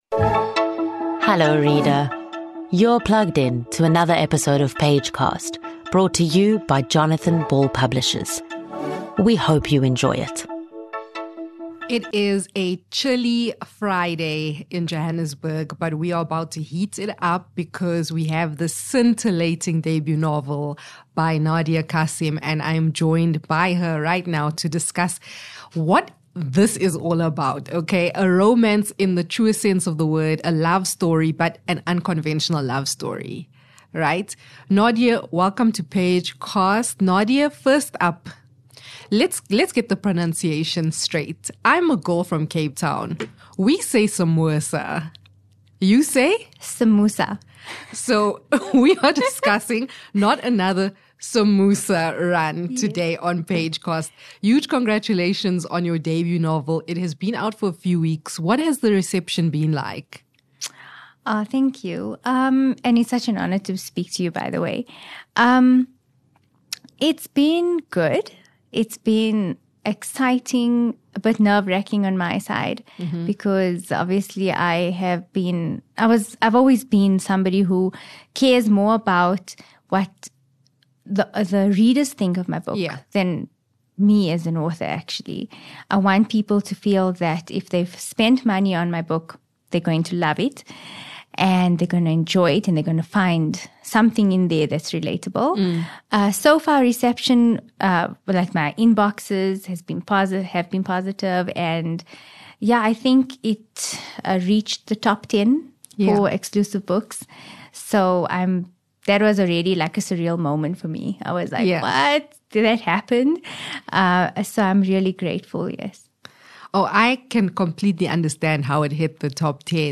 This is a heartfelt conversation about the vibrant stories, rich cultural nuances, and powerful themes that make this book a unique and engaging local read.